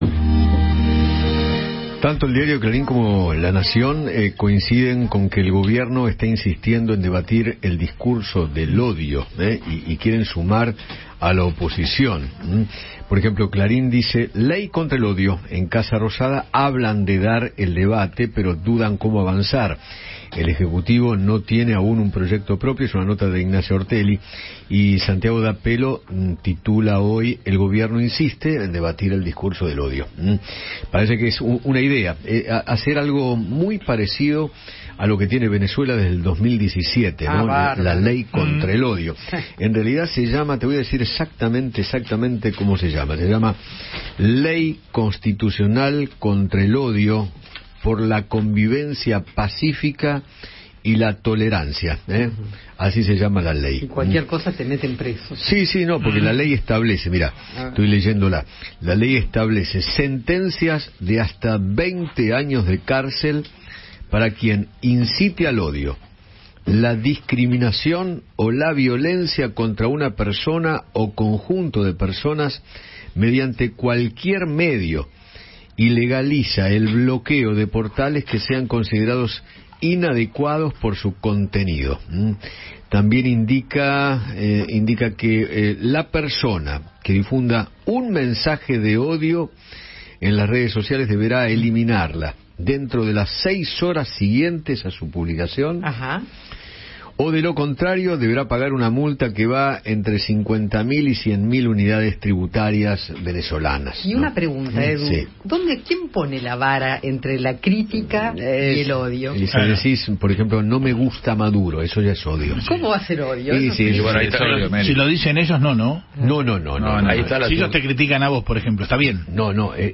El diputado Ricardo López Murphy dialogó con Eduardo Feinmann sobre “la ley del odio” que quiere implementar el Gobierno Nacional y manifestó que “no me van a callar. Yo voy a seguir expresando lo que creo que la ciudadanía necesita que exprese”.